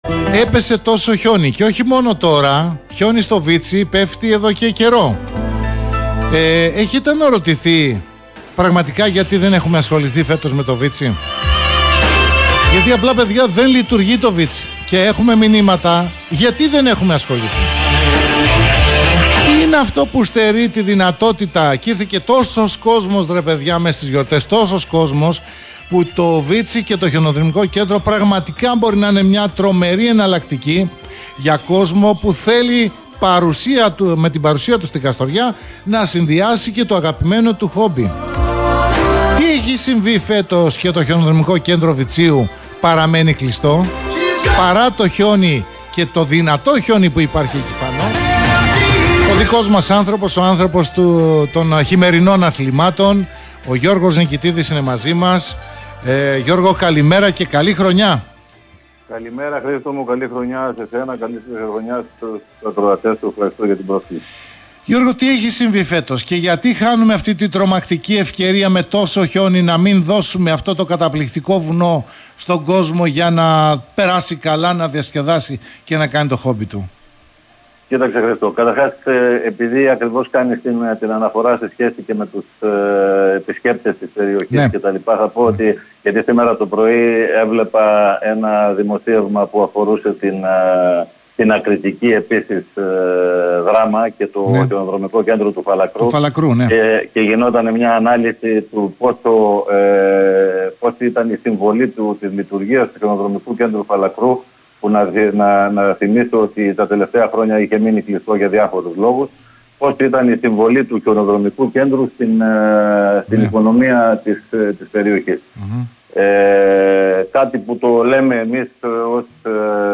Γιατί είναι κλειστό το Χιονοδρομικό Βιτσίου; Πότε θα επαναλειτουργήσει; Συνέντευξη